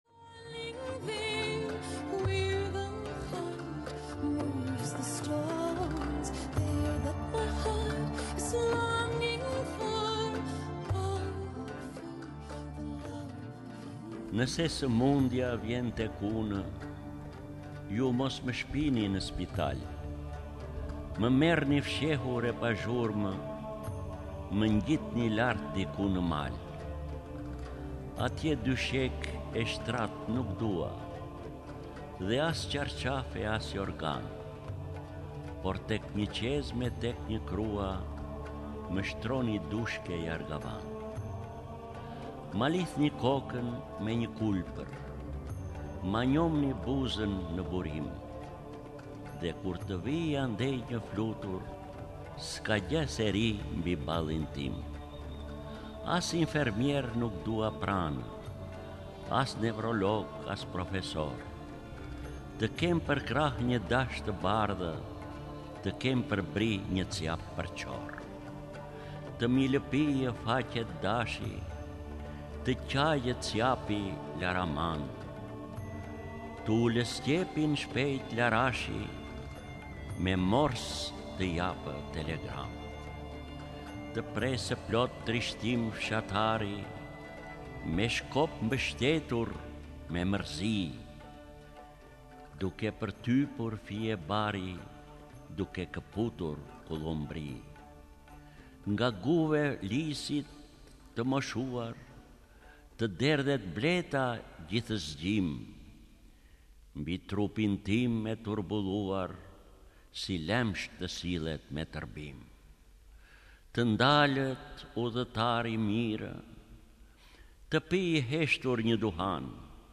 D. AGOLLI - MË PRIT SE PO VIJ SHPEJT Lexuar nga D. Agolli KTHEHU...